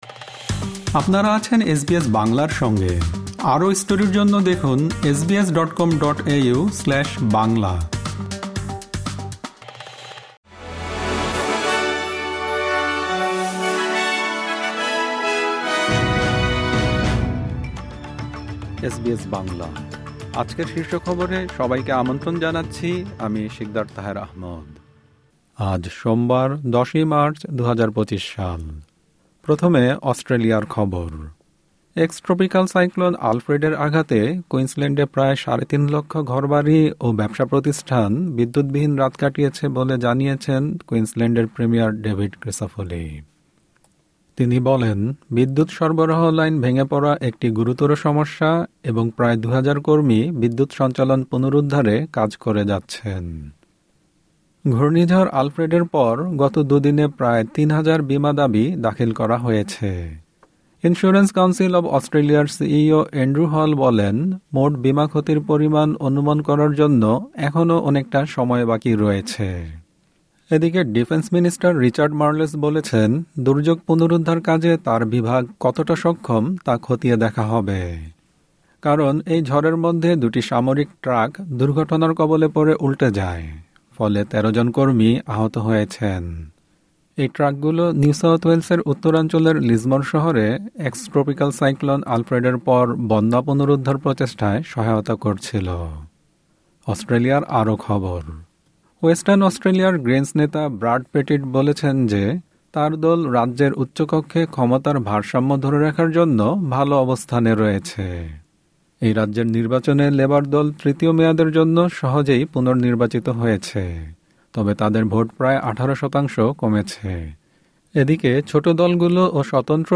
এসবিএস বাংলা শীর্ষ খবর: ১০ মার্চ, ২০২৫